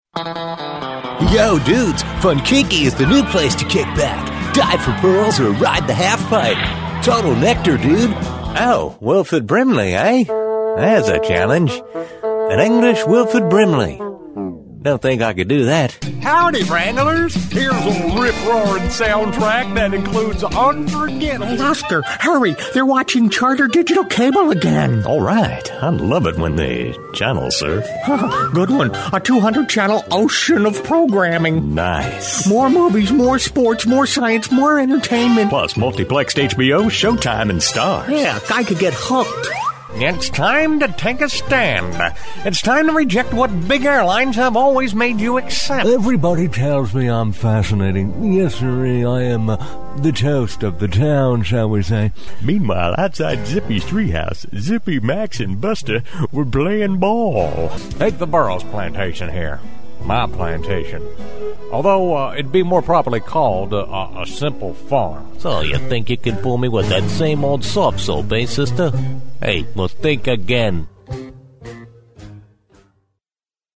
Male
The vibe is Warm, Friendly, Familiar, and Trustworthy, with just enough Edge to keep it interesting.
Character / Cartoon
Surfer - Southern - Originals